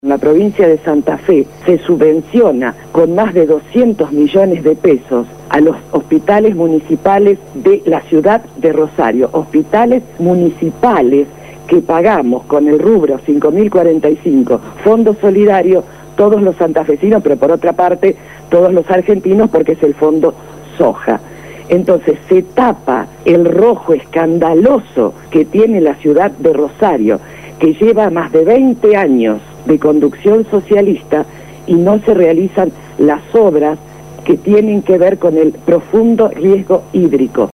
habló esta mañana en el programa Punto de Partida de Radio Gráfica FM 89.3 sobre el panorama de la ciudad de Santa Fe luego de la tormenta de los últimos días.